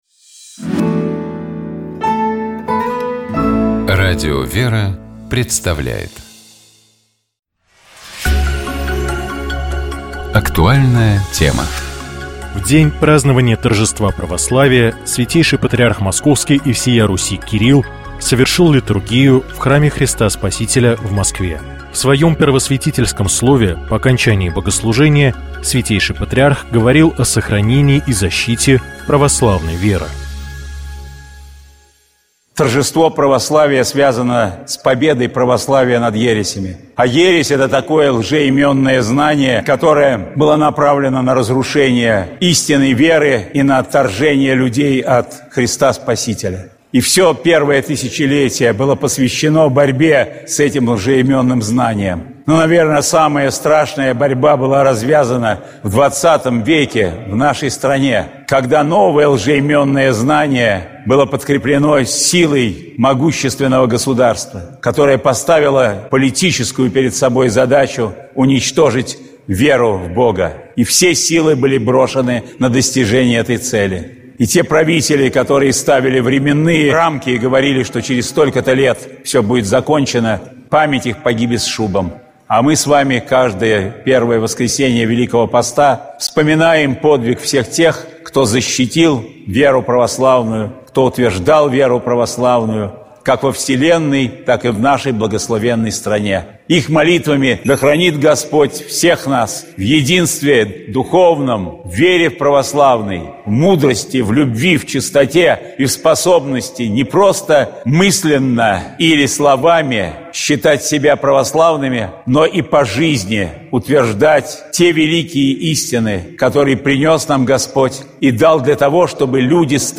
У нас в гостях побывал поэт, драматург, автор песен Карен Кавалерян.